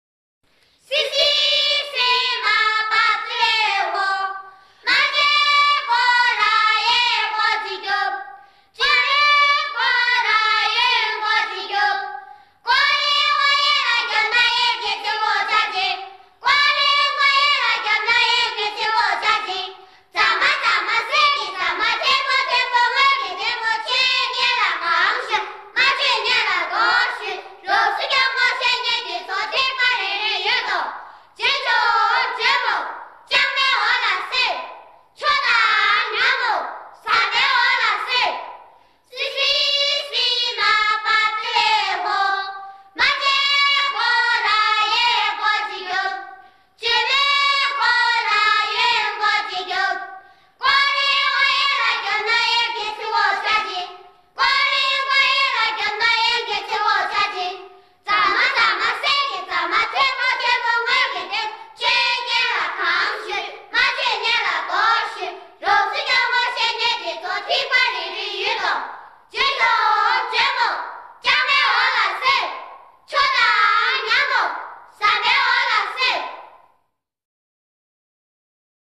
收錄了藏族民歌、歌舞、戲曲、曲藝音樂和宗教音樂等豐富的音樂類型